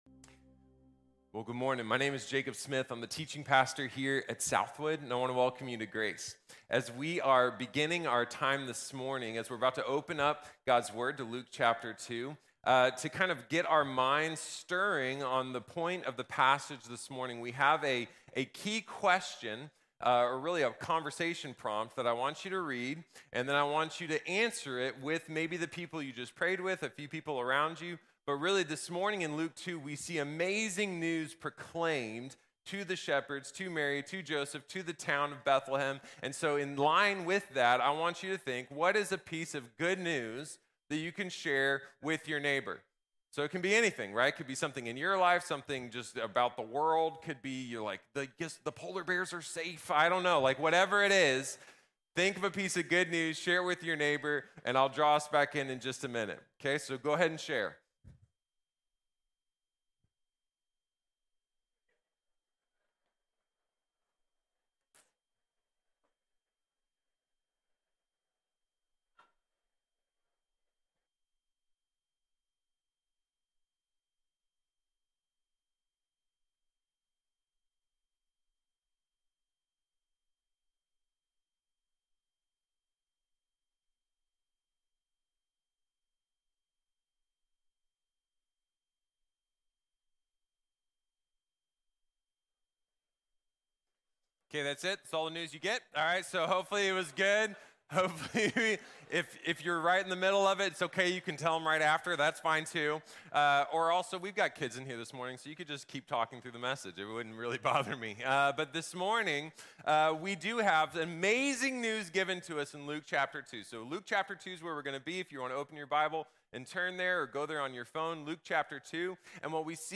Proclamation & Multiplication | Sermon | Grace Bible Church